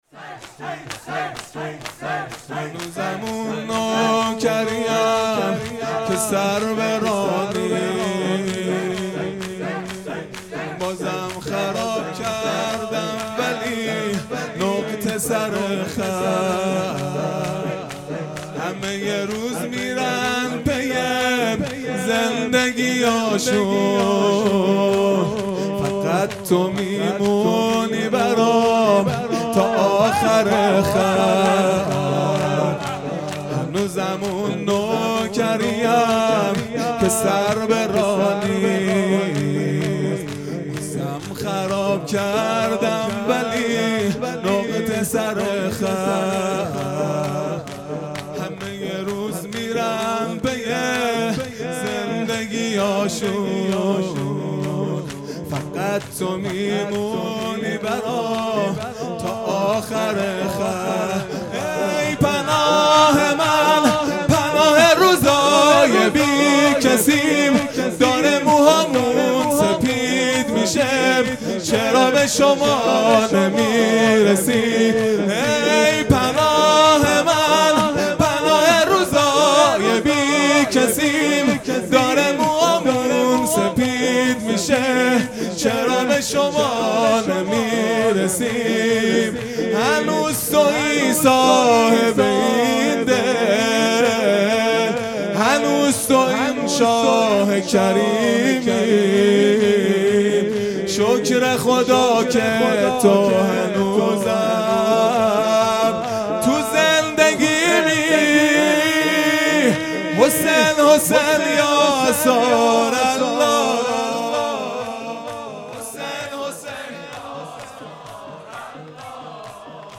خیمه گاه - هیئت بچه های فاطمه (س) - شور | هنوزم اون نوکریم که سر به راه نیست | 26 آذر 1400
فاطمیه 1443 | شب دوم